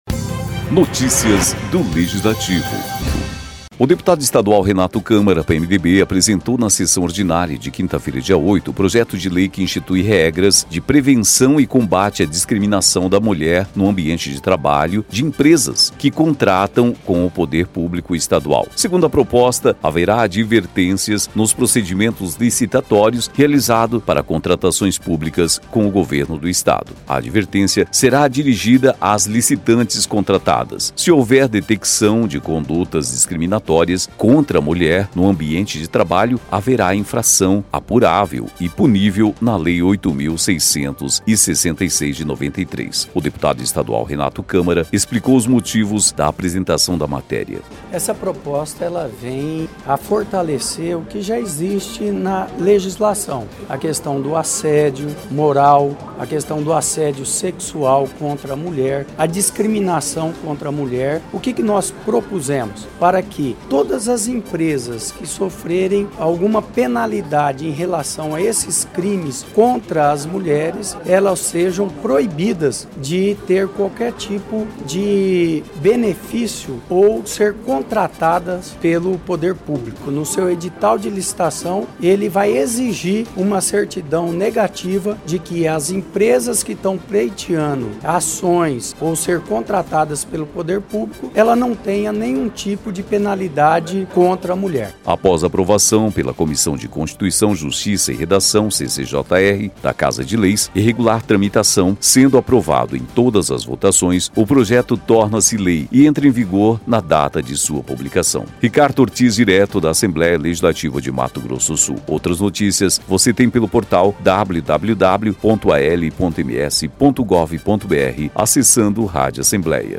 O deputado estadual Renato Câmara explica os motivos da apresentação da matéria.